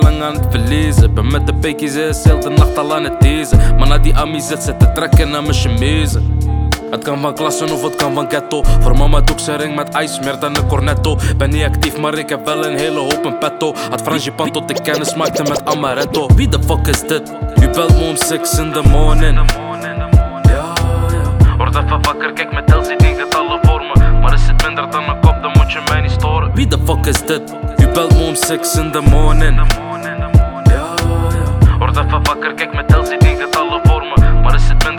# Hip-Hop